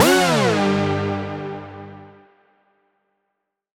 Index of /musicradar/future-rave-samples/Poly Chord Hits/Ramp Down